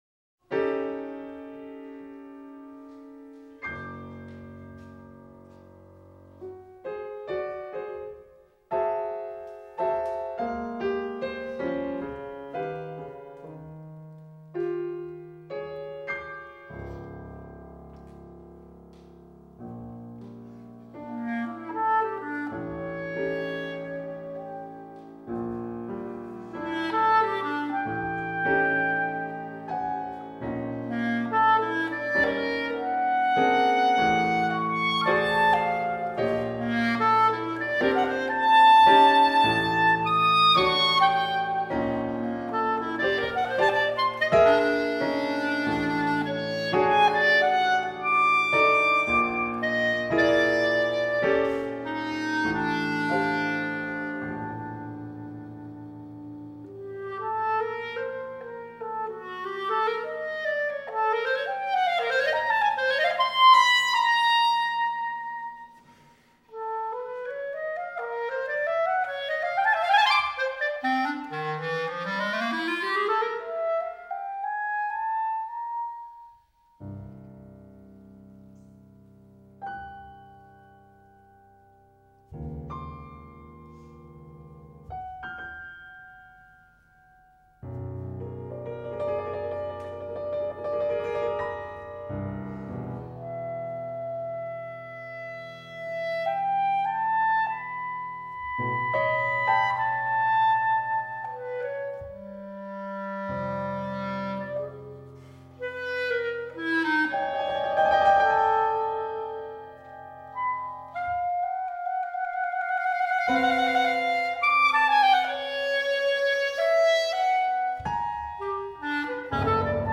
Musica da camera per clarinetto e pianoforte
Musica da camera per clarin.mp3